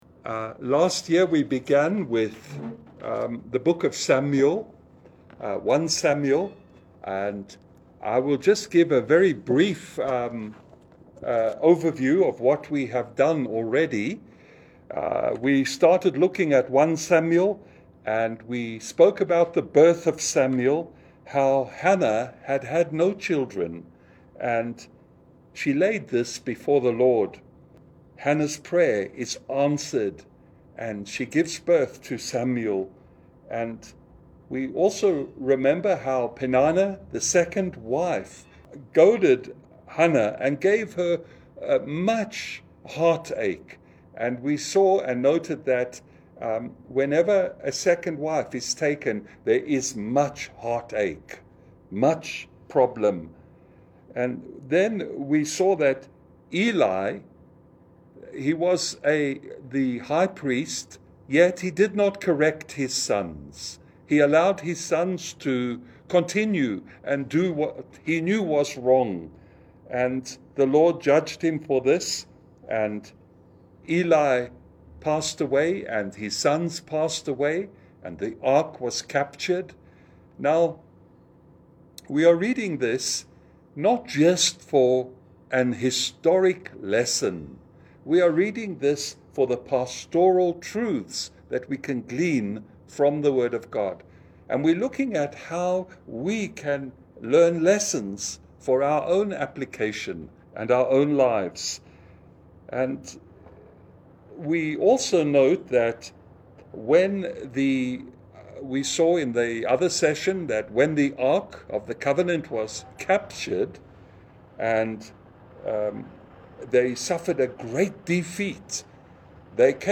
Wednesday Bible Study at Maxoil Hotel Nanyuki from 1pm to 2pm lunch hour. Expositional preaching